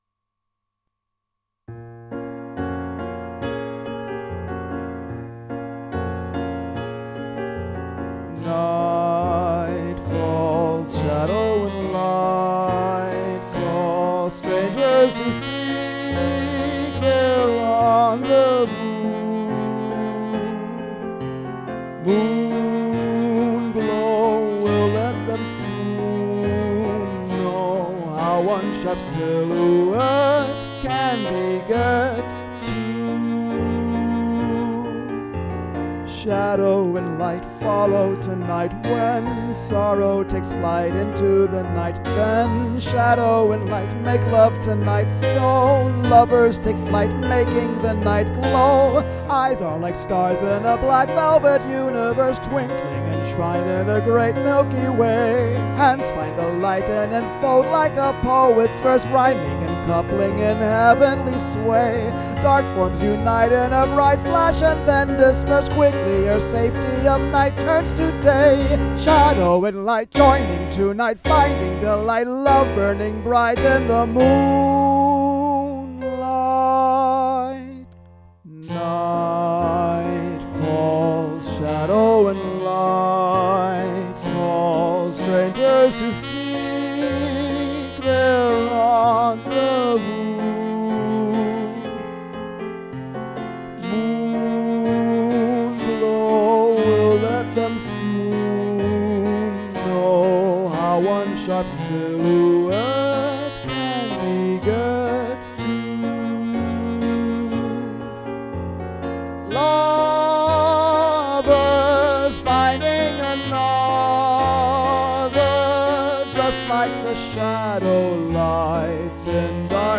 A long run of triplets in the vocal line, over a driving 4/8 rhythm is featured twice in the Speakeasy song “Shadow and Light” (you can hear them begin at the 0:49 and 2:18 marks in the demo recording):